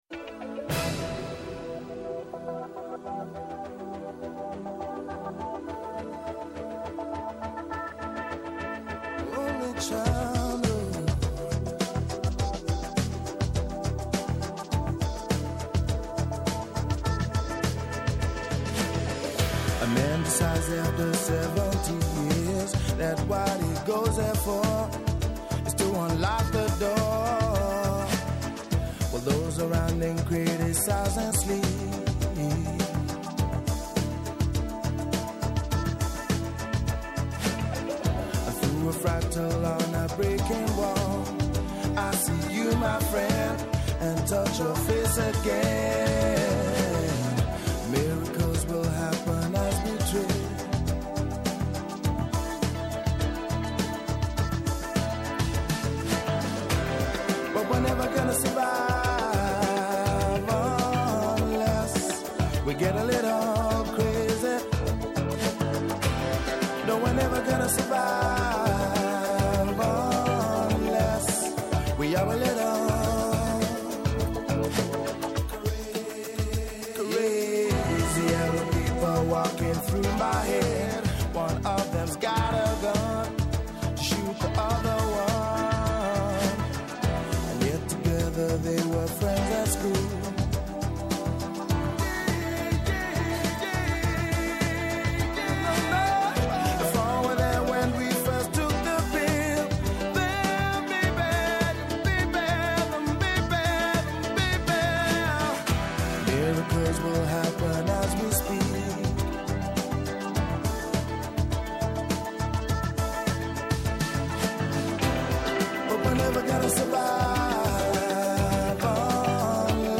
Καλεσμένοι σήμερα : -Ο Θοδωρής Κολυδάς, Διευθυντής Εθνικού Μετεωρολογικού Κέντρου ΕΜΥ